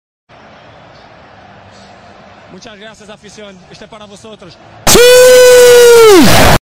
Ronaldo Suiiiiii Earape